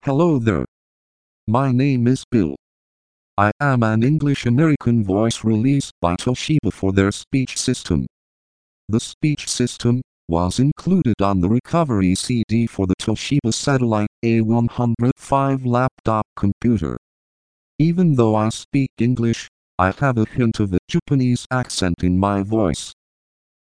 A really obscure text to speech voice released by Toshiba.